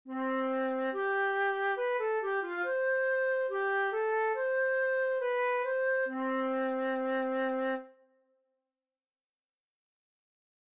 Una ligadura de expresión y un calderón